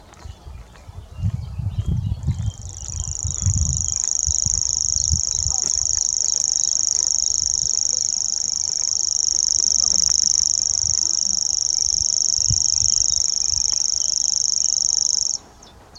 De eerste indruk is dat er een insect zit te zingen, maar het gezang is luider, en het gaat langer door.
Zijn geluid heeft een lange triller.
Soms verandert de klank van het geluid doordat de vogel zijn kop draait.
Sprinkhaanzanger.mp3